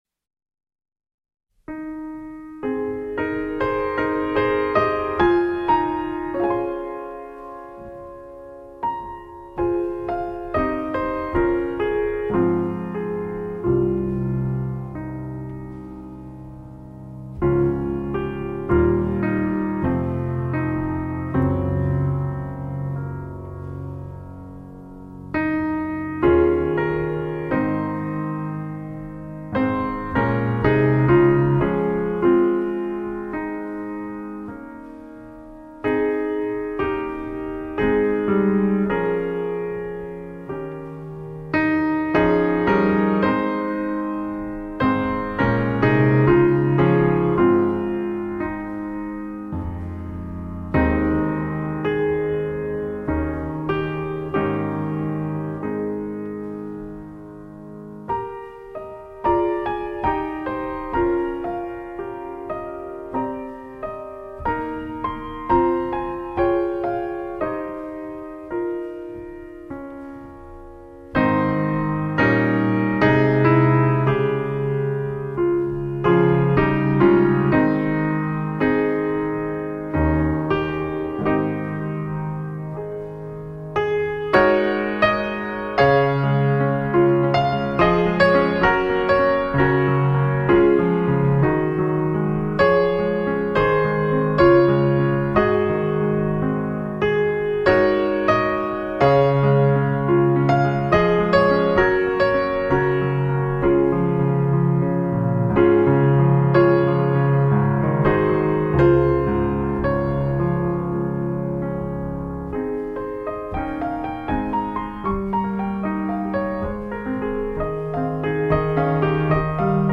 PIANO SOLO Sacred Hymn Favorite, Piano Solo
DIGITAL SHEET MUSIC - PIANO SOLO